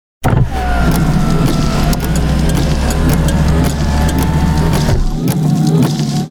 FUNkvíz: Zvuky auta
Definuj tri zvuky z mixu a vyhraj kuchynské spotrebiče.
Definuj tri zvuky spojené s cestovaním, keďže nás čakajú dni presúvania sa po celom Slovensku.